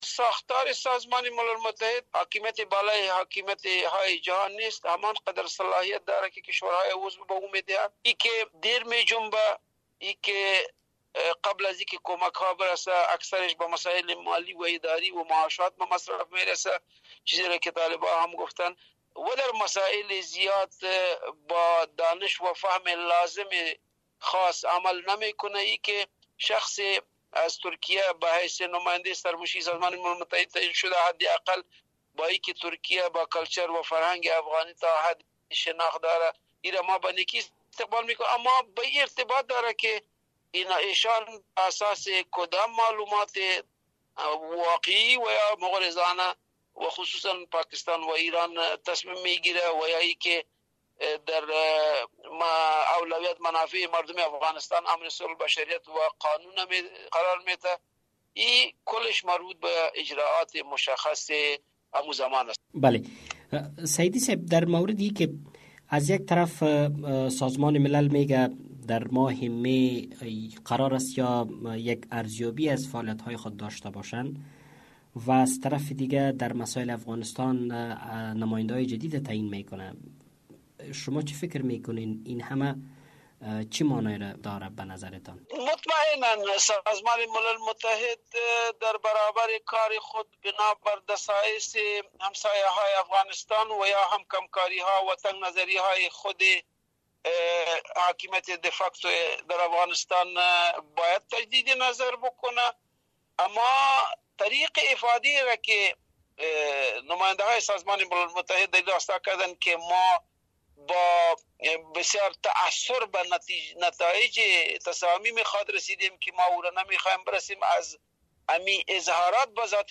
مصاحبه کرده است